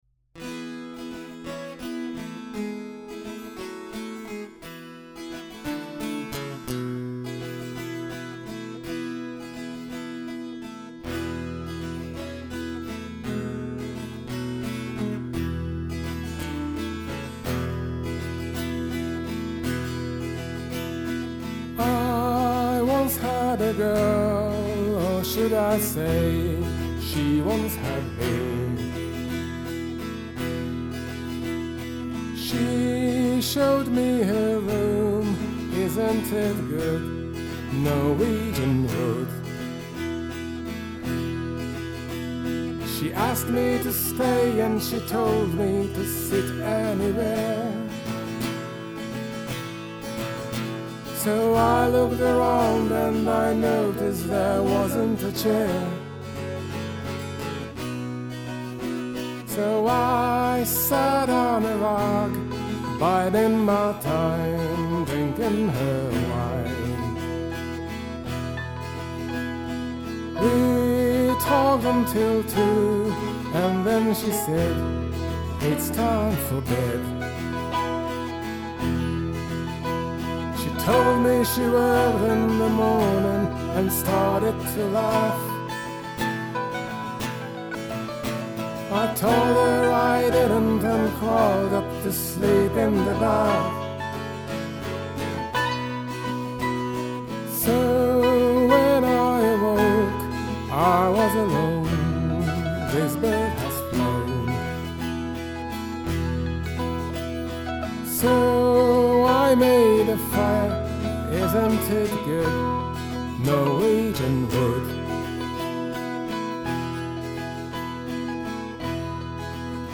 mandolin